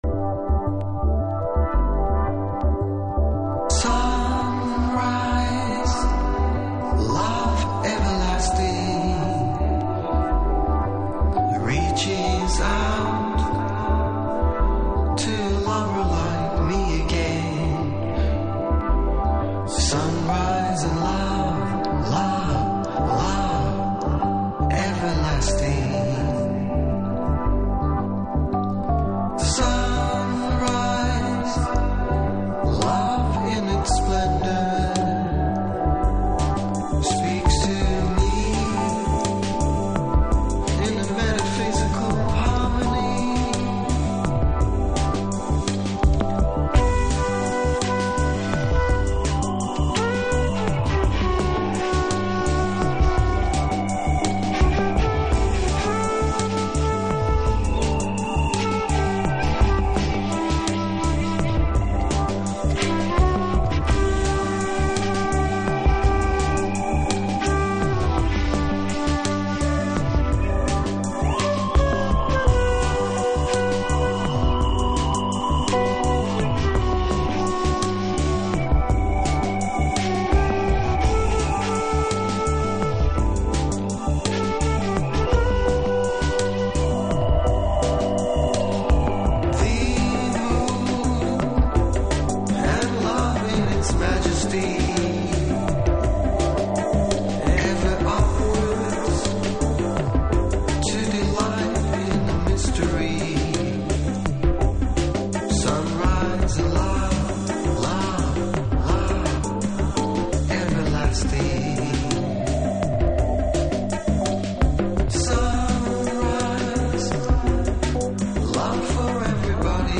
TOP > Future Jazz / Broken beats > VARIOUS